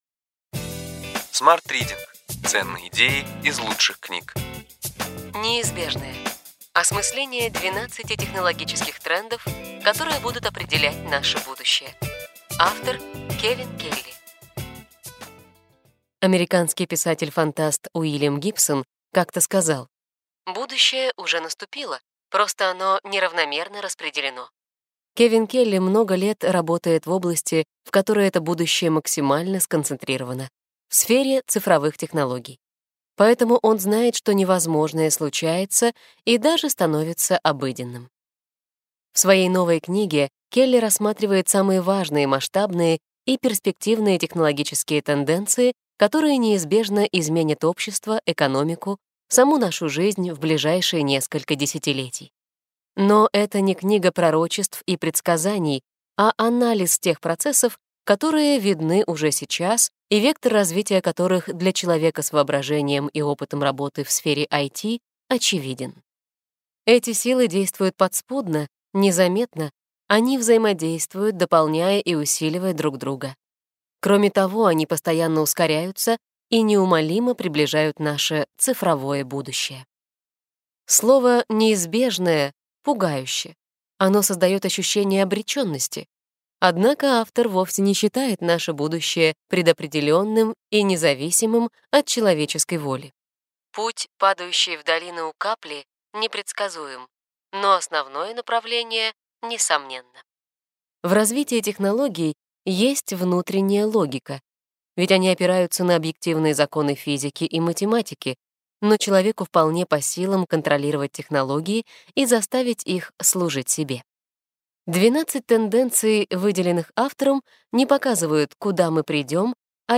Аудиокнига Ключевые идеи книги: Неизбежное. Осмысление 12 технологических трендов, которые будут определять наше будущее.